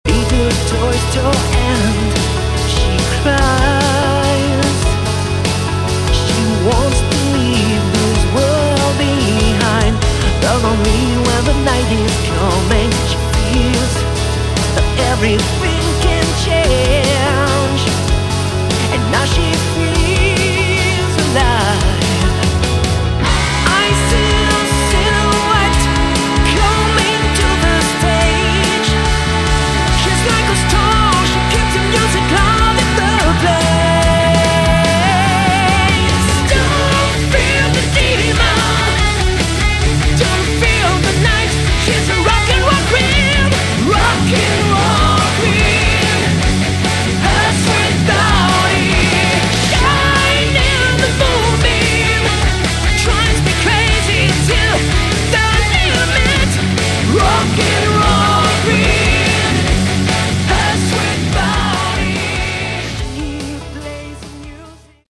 Category: Melodic Rock
vocals
guitars
keyboards
bass
drums